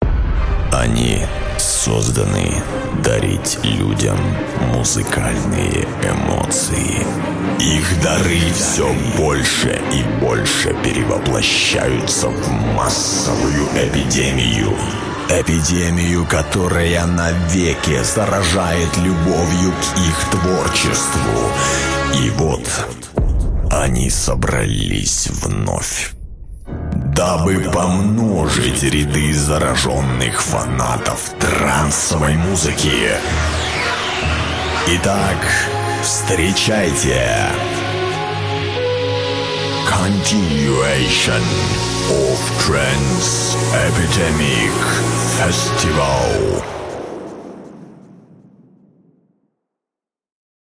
Категория: Скачать Фразы и Произношения